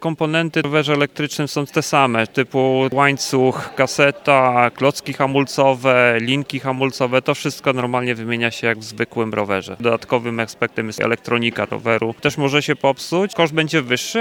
– „Coraz więcej osób pyta o rowery elektryczne, bo to świetne rozwiązanie dla tych, którzy chcą pokonywać dłuższe dystanse bez dużego wysiłku” – wyjaśniał serwisant z jednego ze szczecińskich punktów napraw.